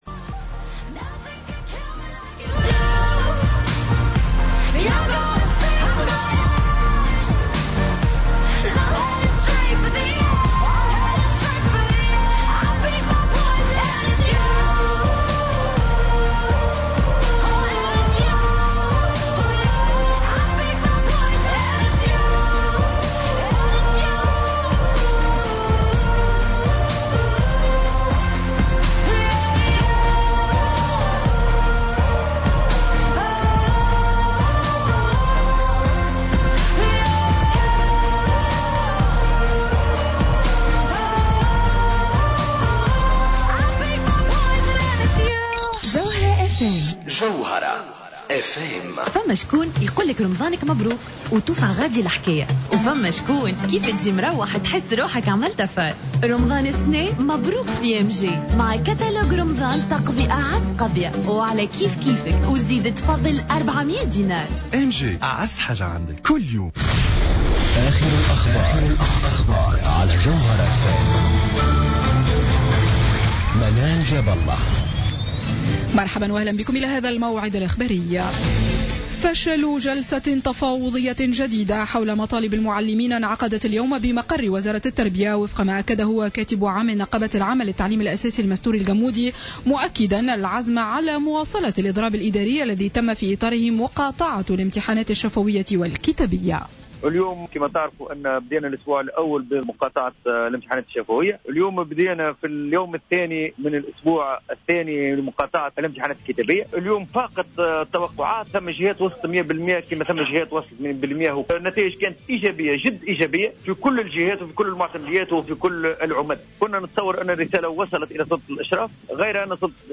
نشرة أخبار السابعة مساء ليوم الاثنين 08 جوان 2015